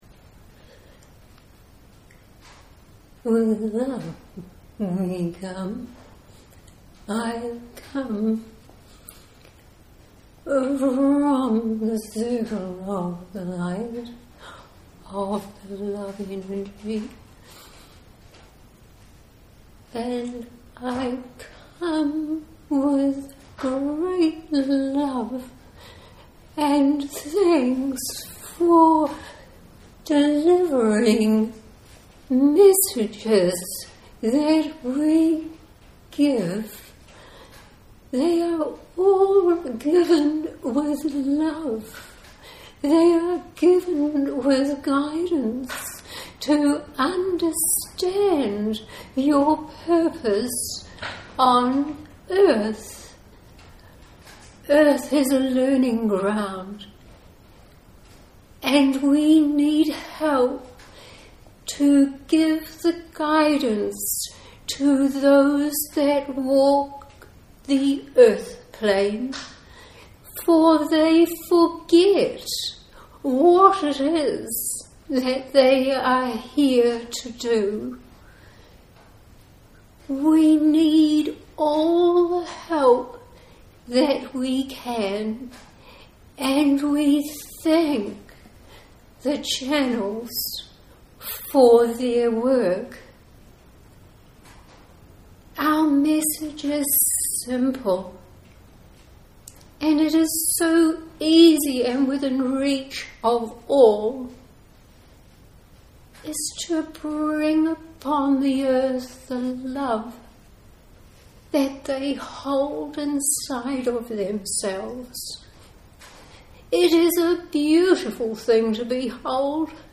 Bear in mind that it is a live recording and please excuse the deficiencies in sound quality.
Posted in Audio recording, Metaphysical, Spirituality, Trance medium